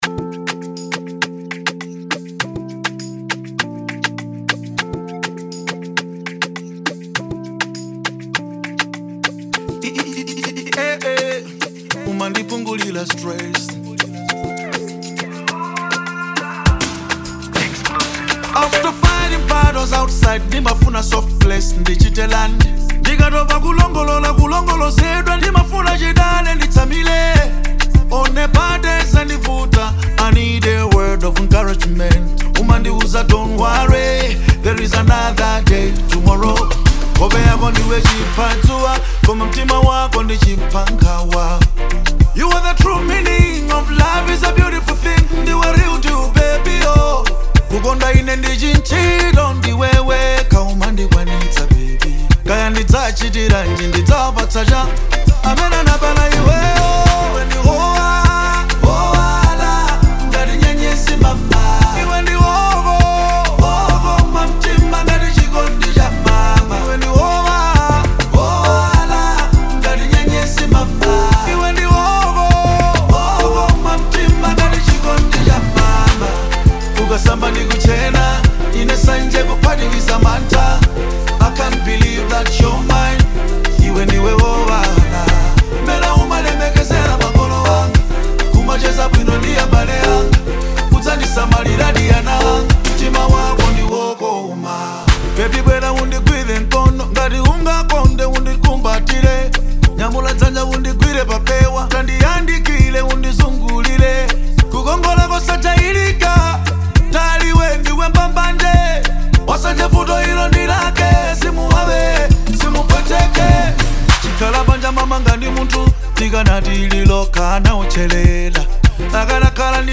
Genre Afrobeat